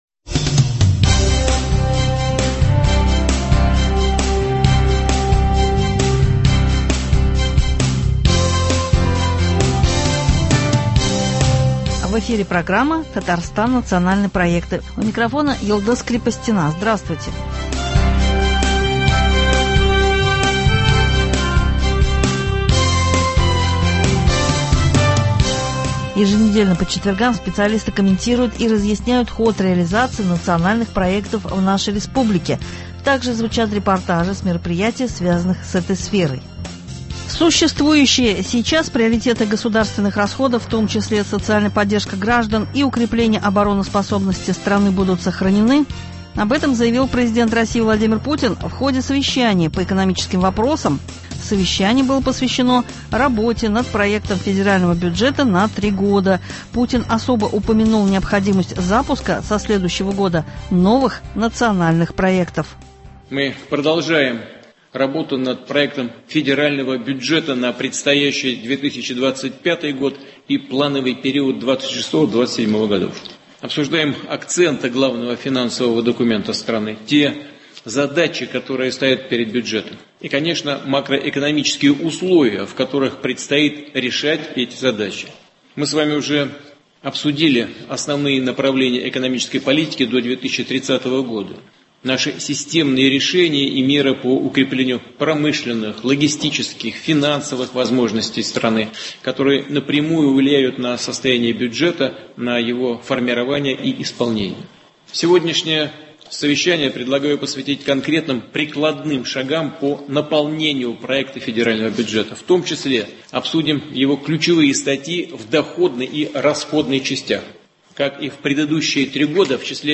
Еженедельно по четвергам специалисты комментируют и разъясняют ход реализации Национальных проектов в нашей республике. Также звучат репортажи с мероприятий, связанных с этой сферой.